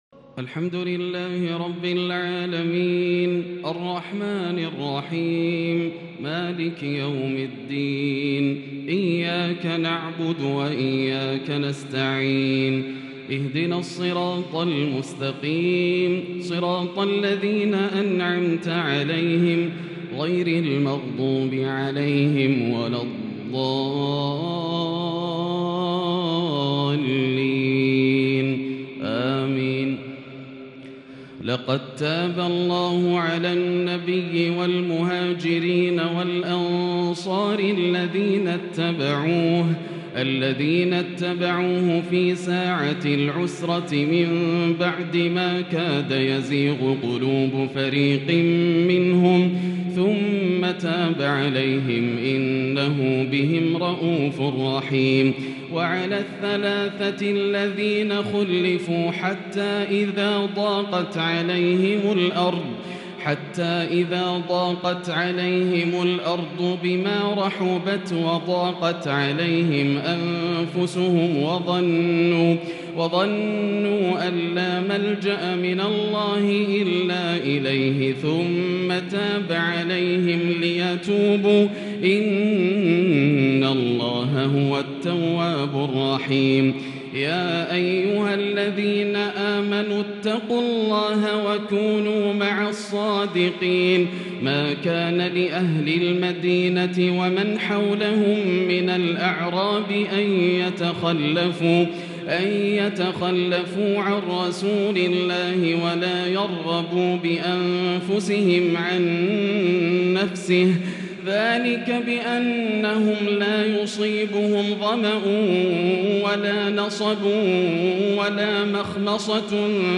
تراويح ليلة 14 رمضان 1442هـ سورة التوبة 117_يونس 1-25 | taraweeh 14st niqht Surah At-Tawba _Surah Yunus 1442H > تراويح الحرم المكي عام 1442 🕋 > التراويح - تلاوات الحرمين